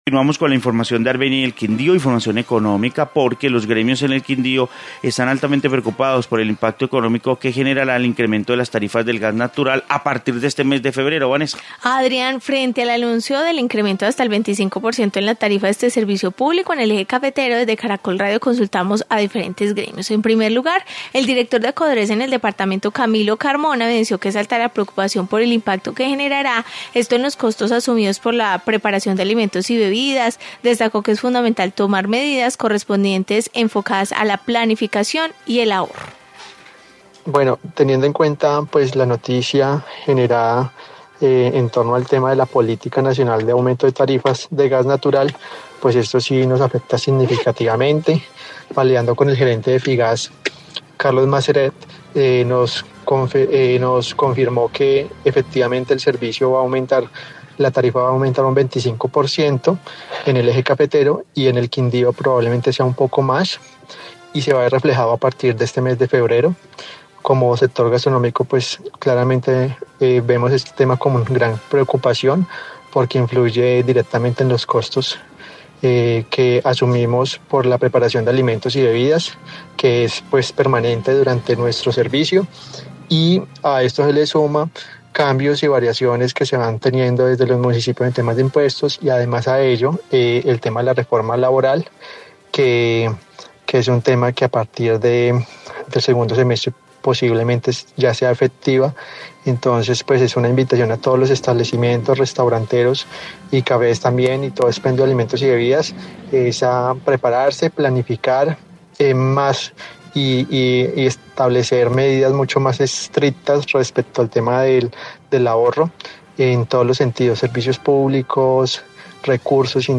Informe de gremios sobre incremento de gas